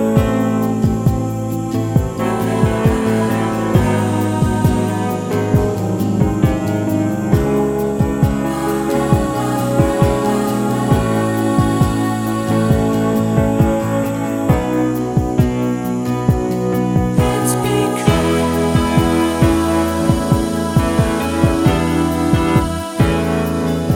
Two Semitones Down Pop (1970s) 5:04 Buy £1.50